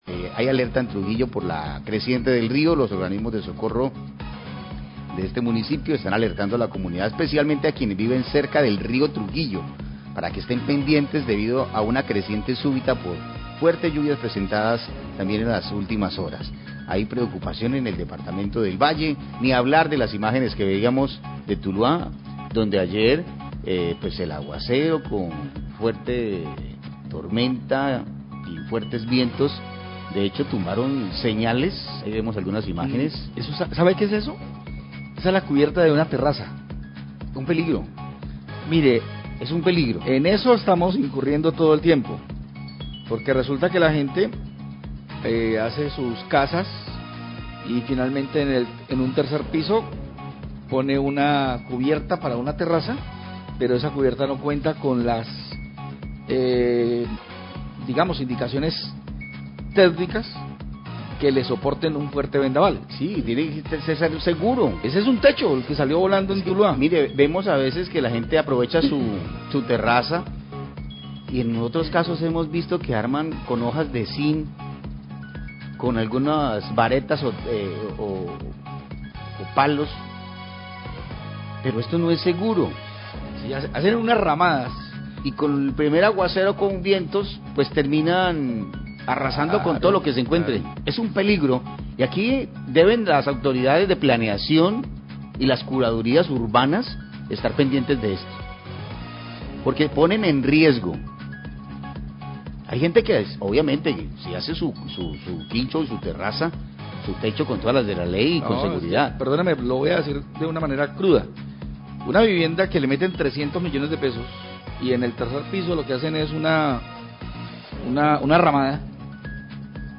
Radio
Los periodistas comentan sobre las emergencias de anoche tras las fuertes lluvias y vendaval en el municipio y destacan que la cubierta de una vivienda del barrio Las Palmas voló por los aires y cayó sobre un transformador. Agregan que hay que tener cuidado con este tipo de construcciones para que no se vuelva a presentar un hecho similar.